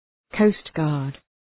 Προφορά
{‘kəʋstgɑ:rd}